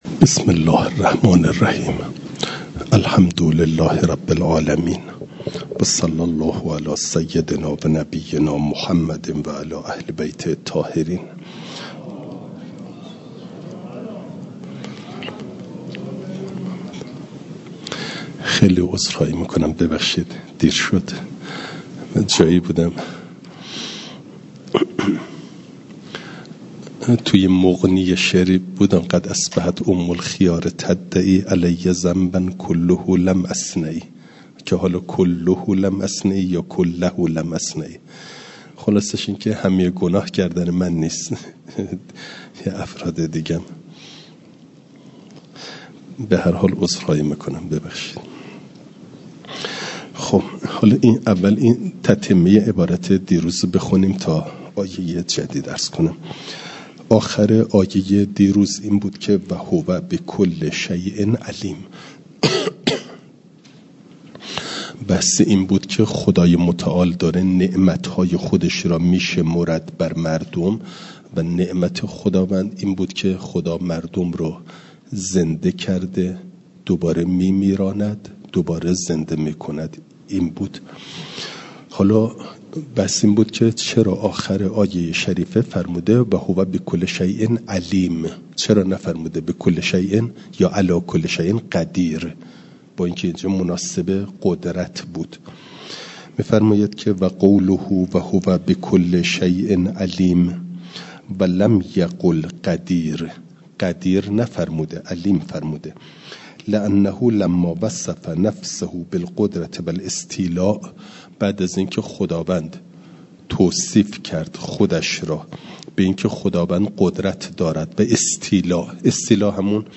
فایل صوتی جلسه سی و هفتم درس تفسیر مجمع البیان